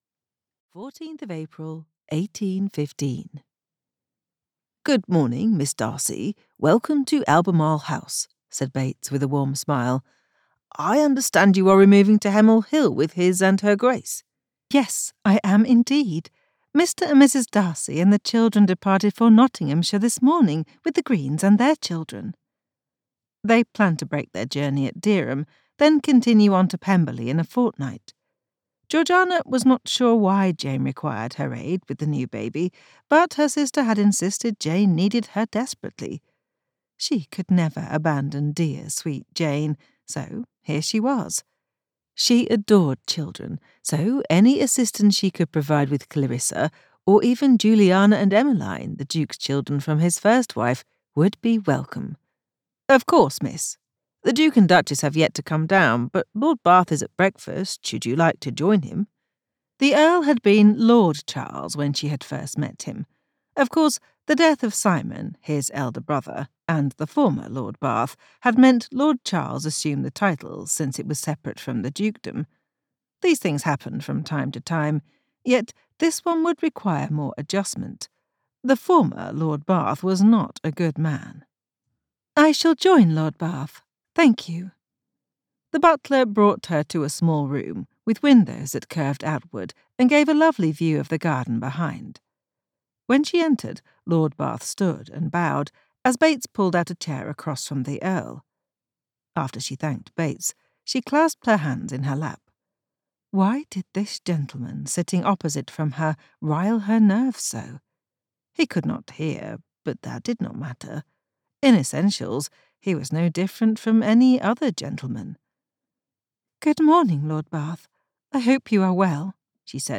Audiobook release celebration: Worthy in Every Way